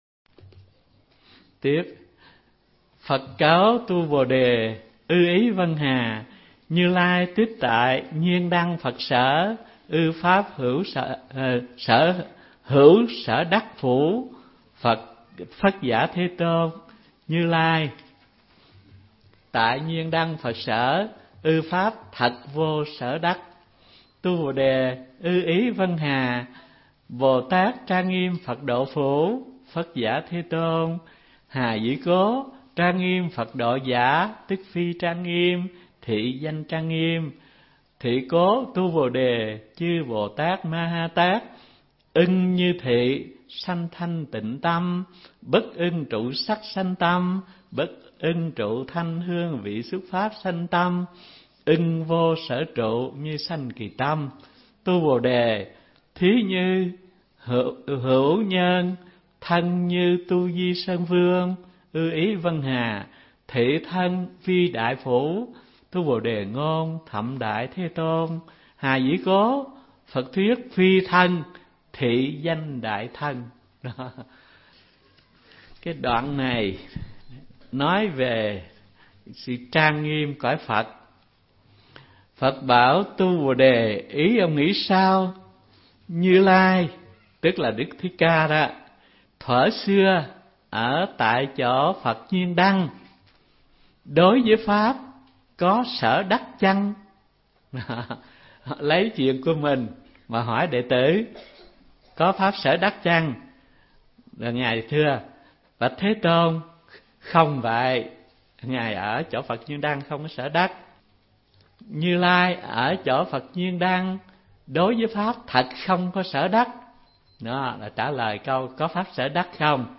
Giảng Kinh Kim Cang 4 – hòa thượng Thích Thanh Từ mp3
Mp3 Pháp Âm Giảng Kinh Kim Cang 4 – Hòa Thượng Thích Thanh Từ